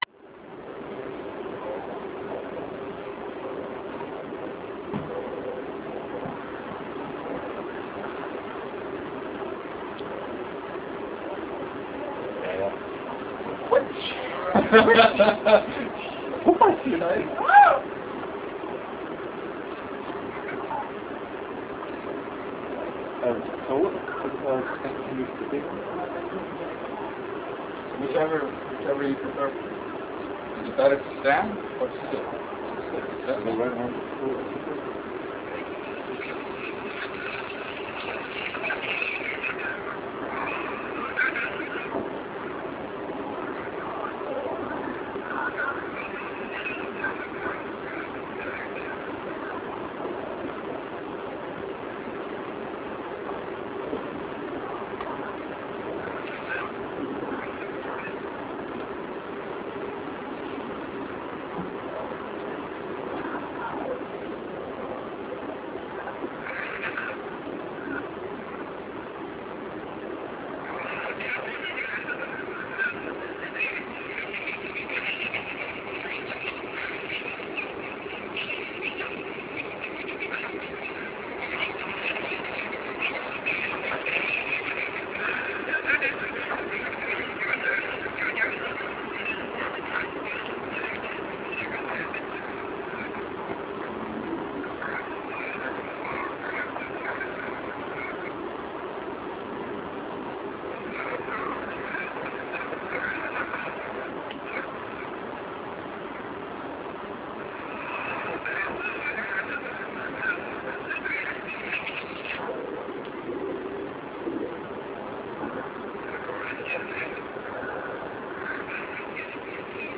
im canstudio weilerswist von 1999